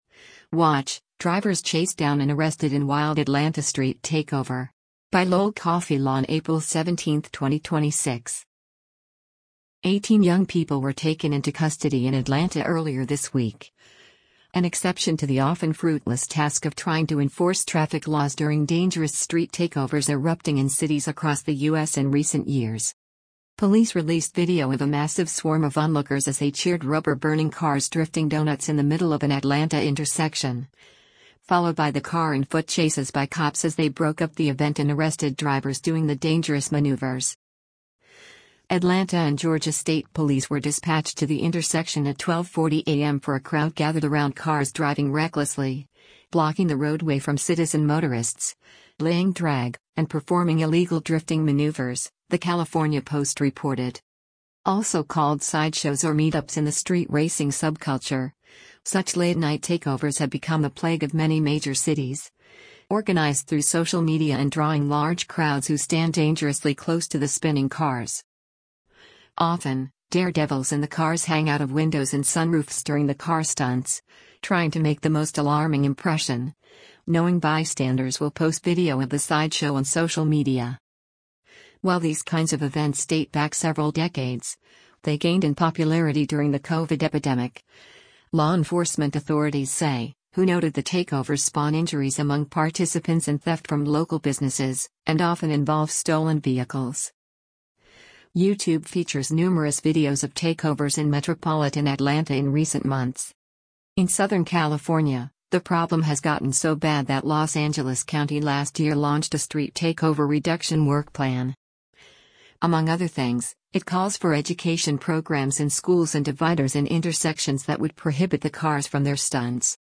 Police released video of a massive swarm of onlookers as they cheered rubber-burning cars “drifting” donuts in the middle of an Atlanta intersection, followed by the car and foot chases by cops as they broke up the event and arrested drivers doing the dangerous maneuvers.
The body cam video of the recent Atlanta takeover shows the arrival of police at the event and the chases leading to the apprehension of some of the racers.
During one foot chase, an officer yells, “You better stop before I taze.”